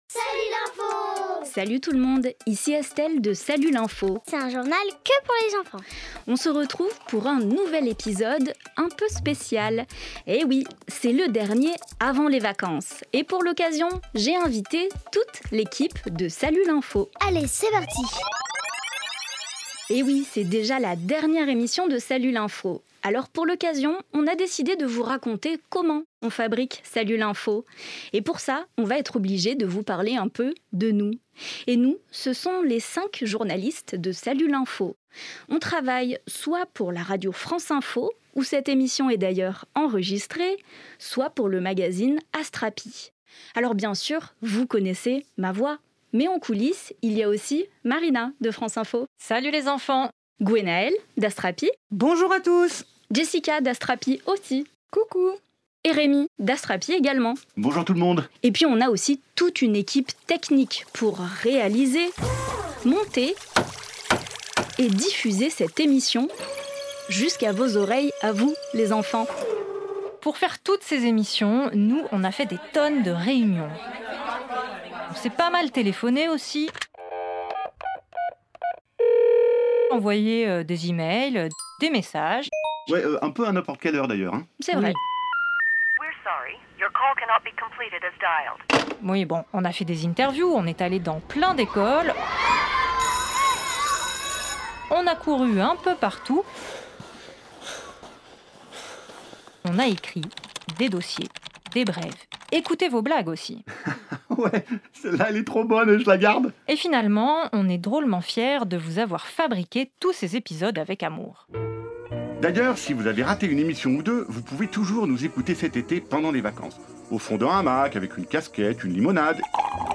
Tu aimerais bien savoir qui fabrique “Salut l’info !” et comment ça se passe en coulisses ? Écoute ce dernier épisode avant les vacances, les 5 journalistes qui préparent ton émission chaque semaine te racontent tout !